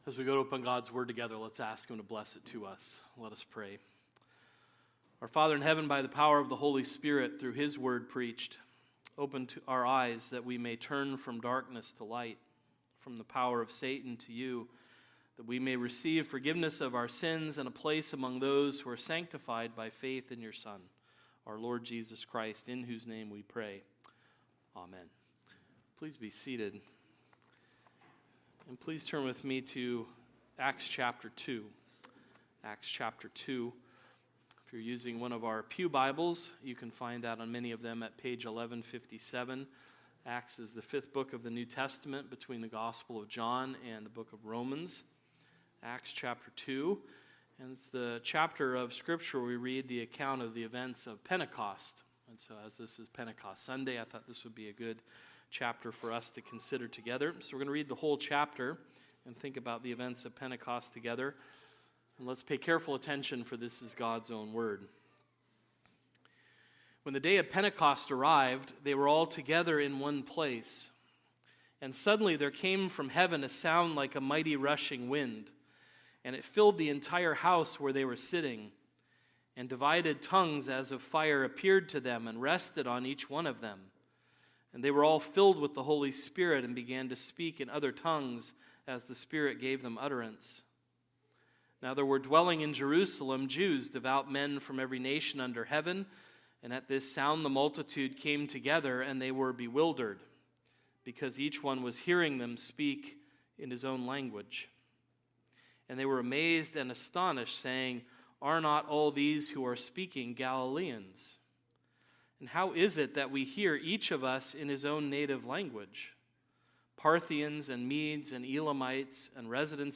Acts of the Apostles — Sermons — Christ United Reformed Church